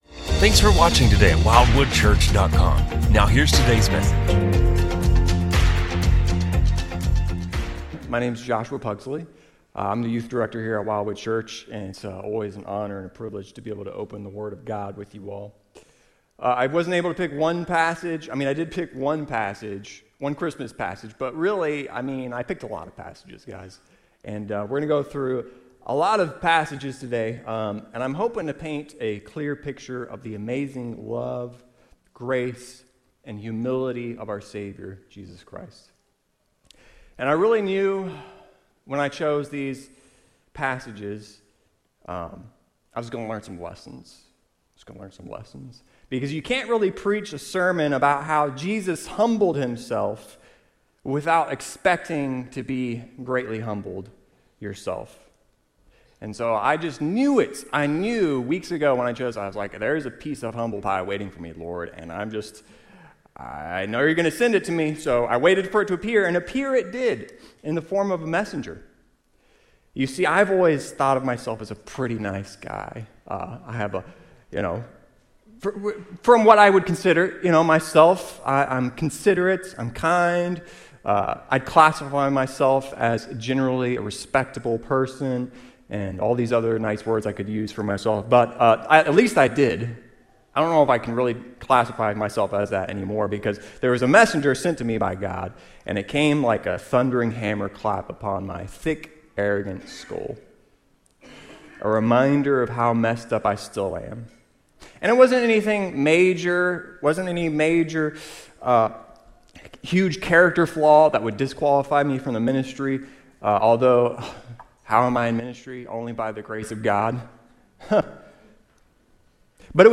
Sermon-Audio-12-3-23.mp3